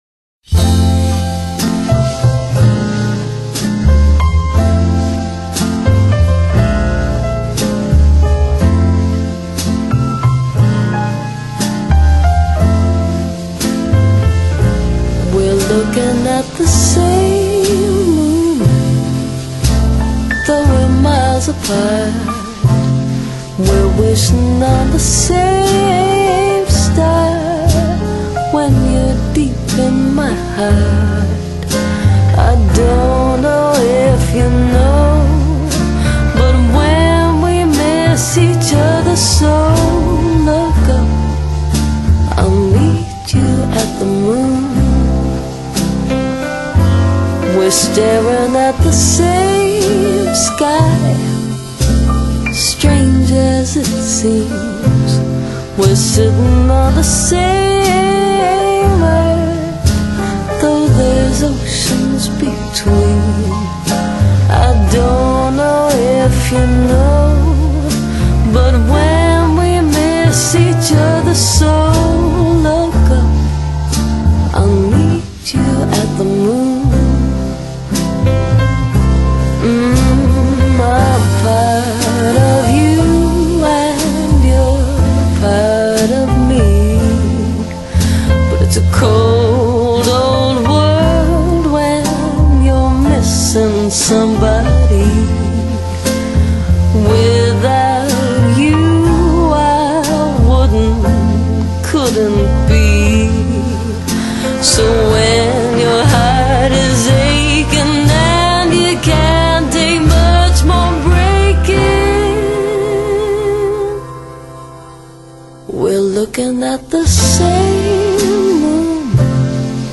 Blues And Jazz Para Ouvir: Clik na Musica.